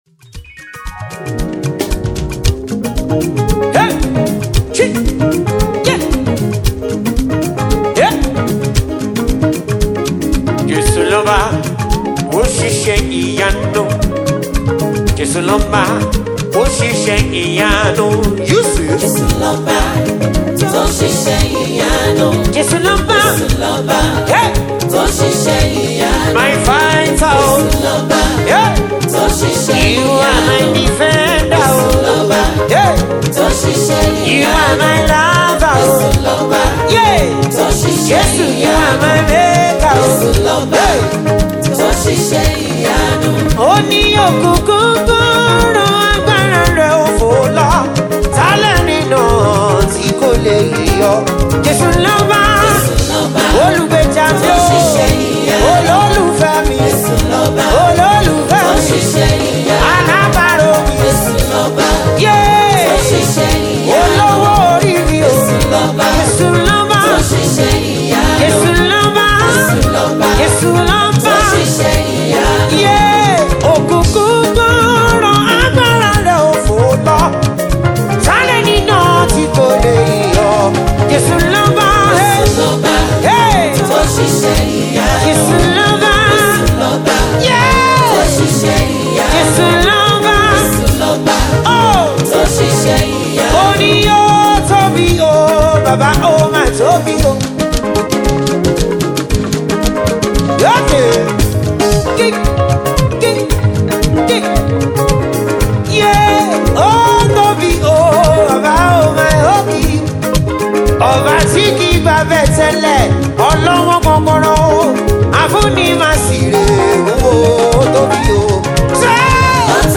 Yoruba Gospel Music
praise and worship